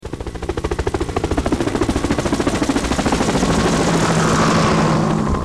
Tiếng xe cộ
Tiếng Xe Cộ – Hiệu Ứng Âm Thanh Giao Thông Chân Thực